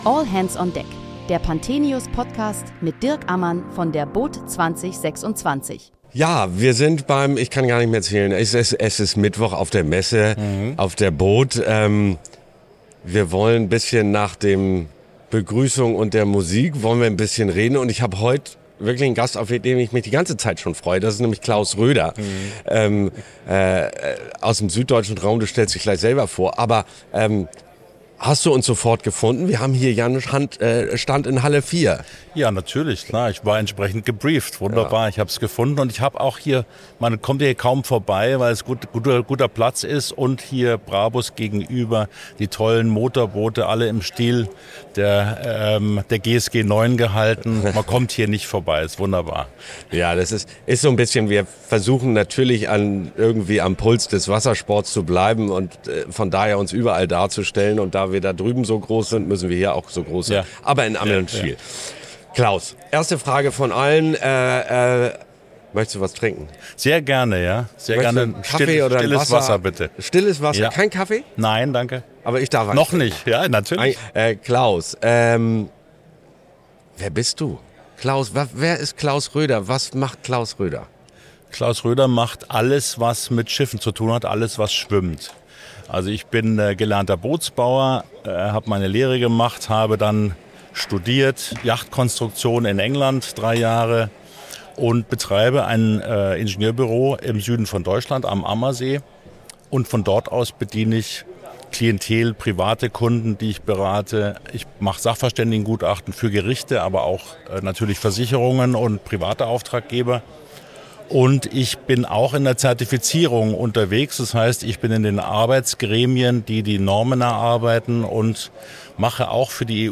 Während der boot 2026 sprechen wir täglich mit Gästen aus der Branche über aktuelle und kontroverse Themen rund um den Wassersport.